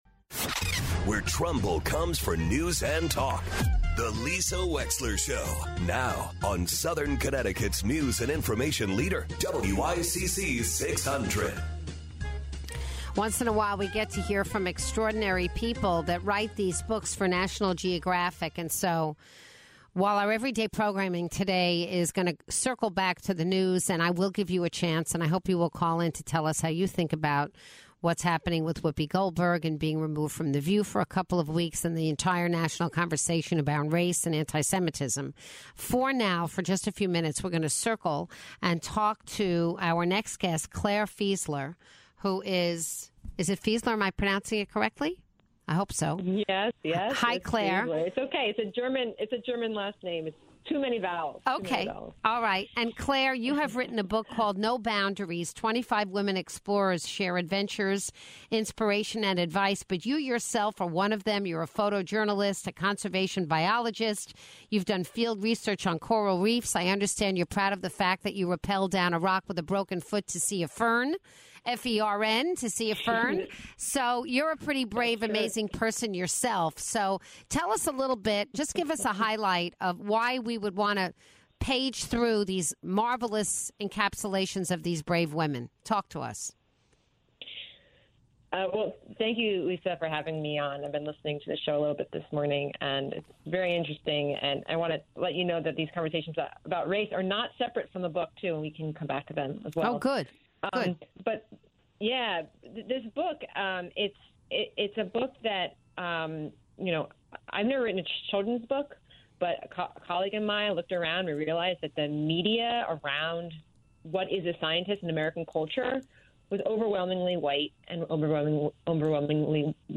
calls in to chat about her latest book featuring female explorers.